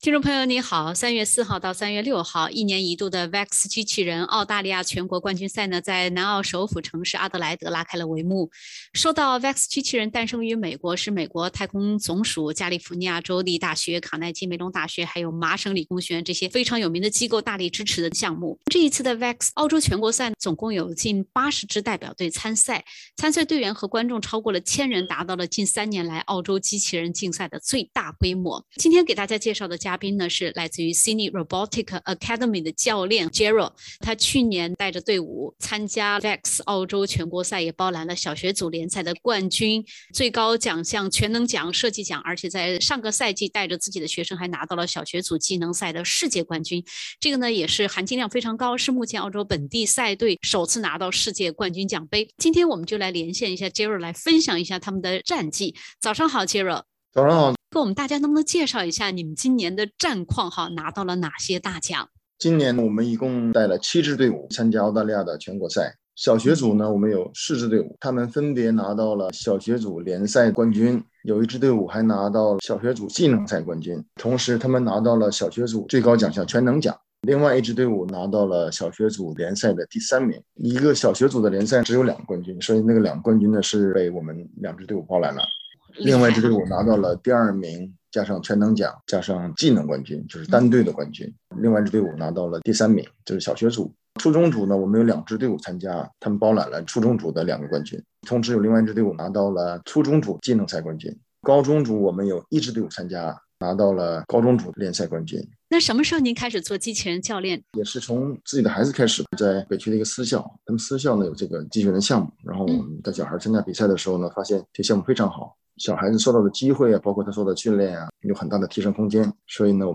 去年VEX澳洲全国赛包揽小学组联赛冠军、最高奖项全能奖等多个大奖的SRA团队，在今年VEX机器人澳大利亚全国冠军赛上再次拿下多个冠军头衔。(点击封面图片，收听完整采访）